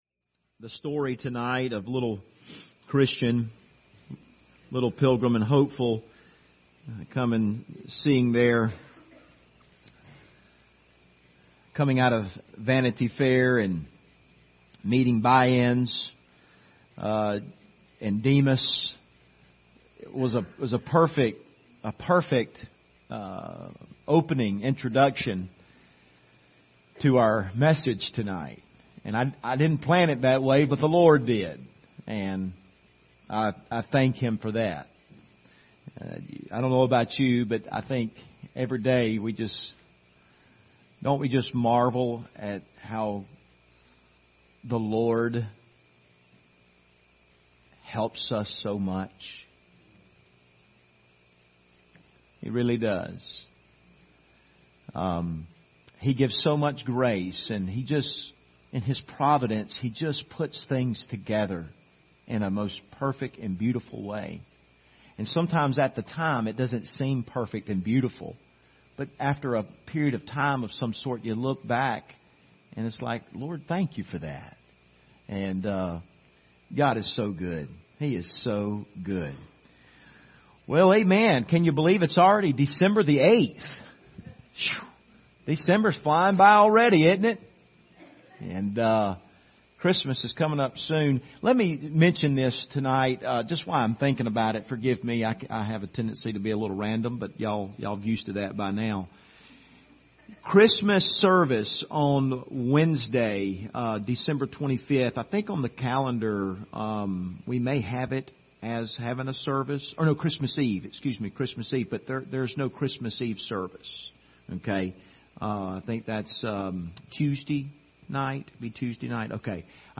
Passage: Romans 16:17-20 Service Type: Sunday Evening